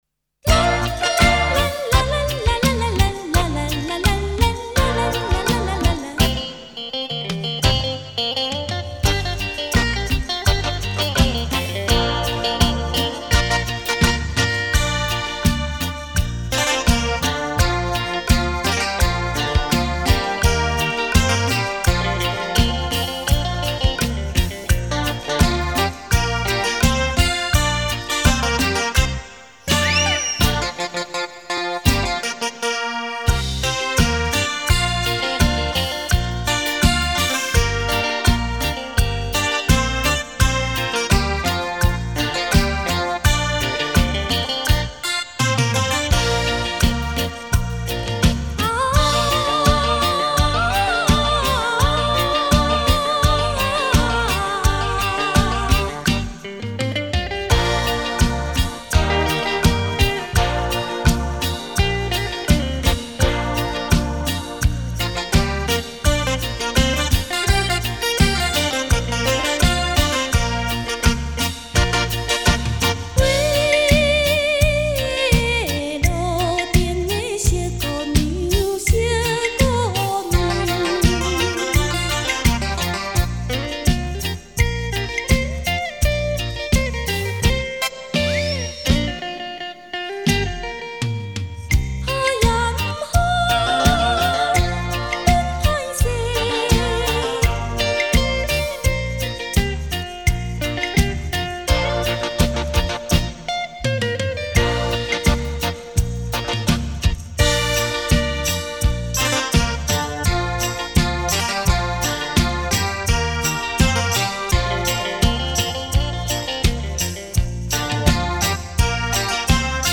[纯音乐]
24k金碟 全数码录音
试音天碟 唯我独尊 震撼音效 无可匹敌